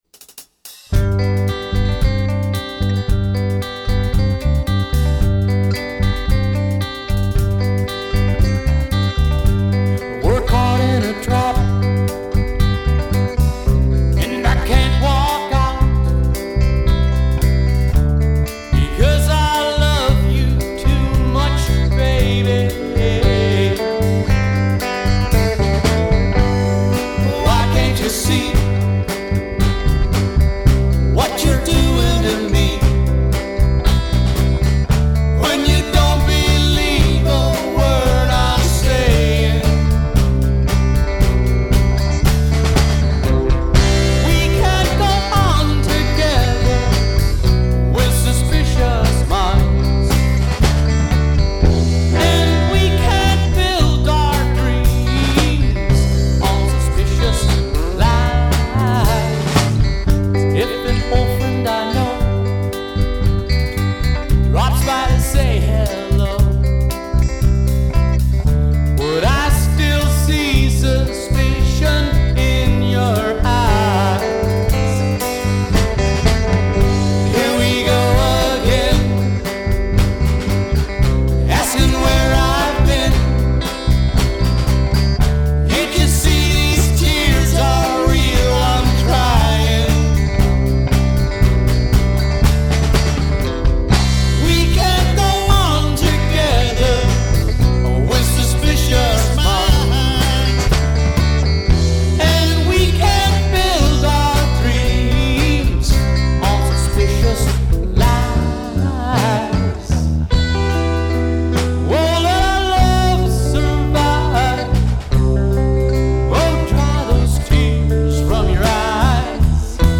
Performing Classic Dance Rock